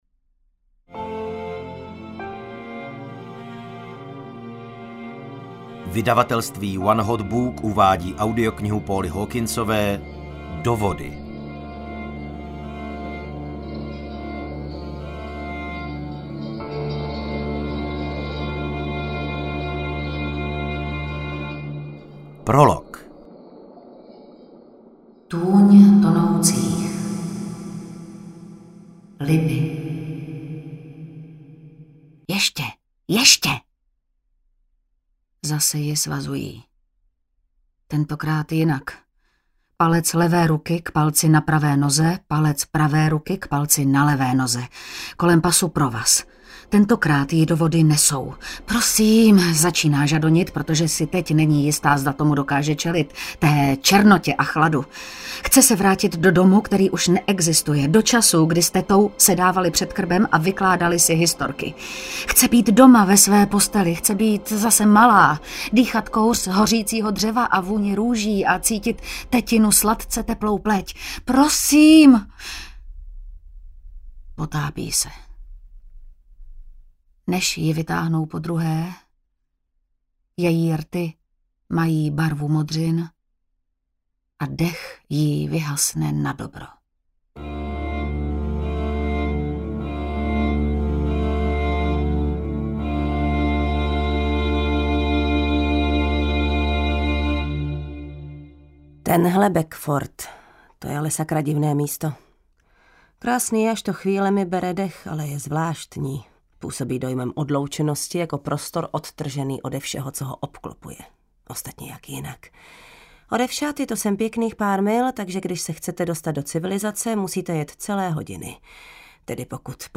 Do vody audiokniha
Ukázka z knihy